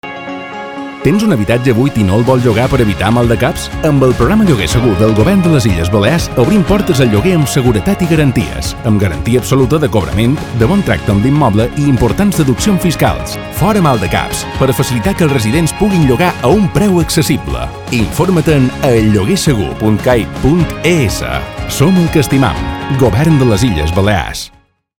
Arxiu Multimedia Falca Lloguer Segur (.mp3)